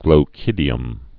(glō-kĭdē-əm)